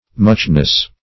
Muchness \Much"ness\, n.